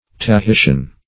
Tahitian \Ta*hi"ti*an\, a.